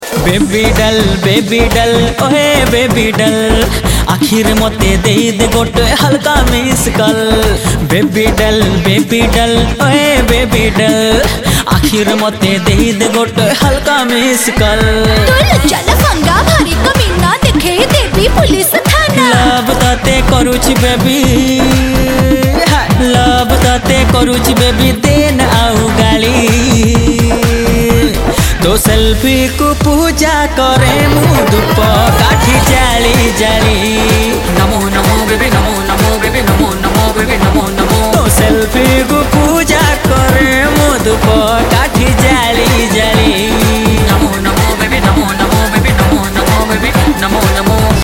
Odia Ringtones
dance song